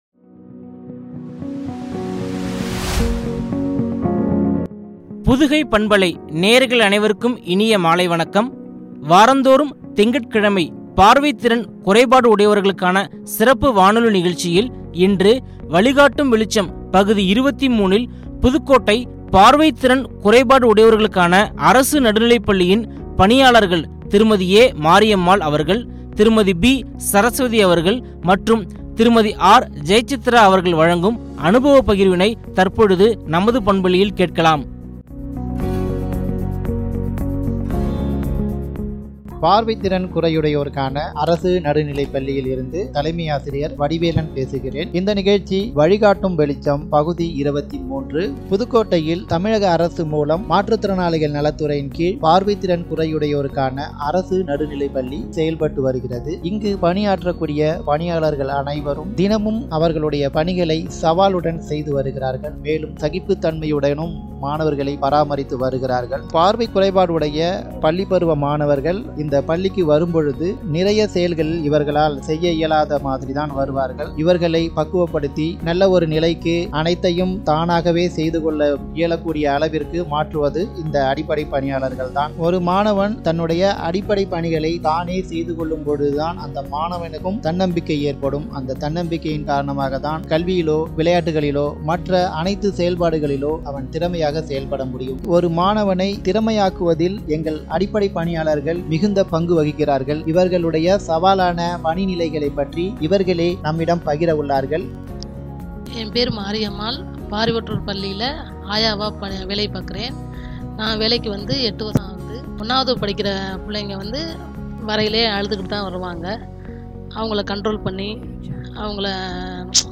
சிறப்பு வானொலி நிகழ்ச்சி
உரையாடல்.